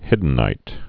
(hĭdn-īt)